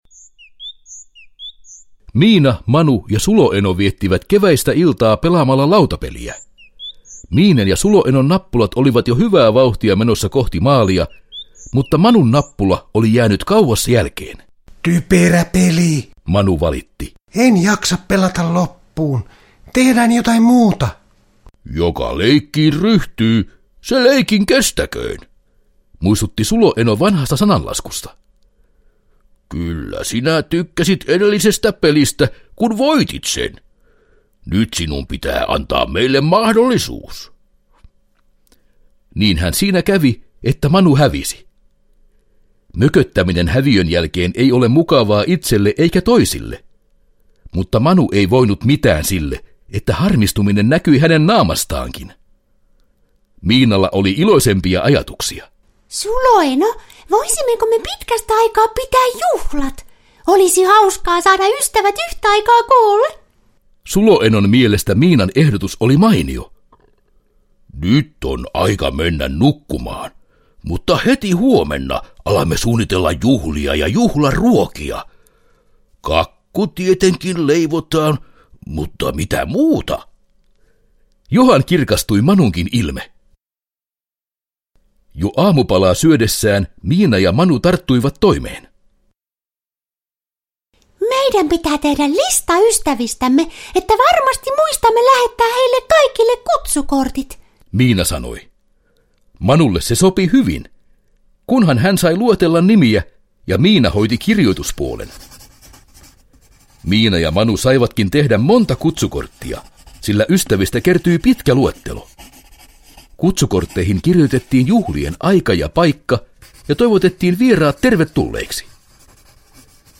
Miina ja Manu juhlivat – Ljudbok – Laddas ner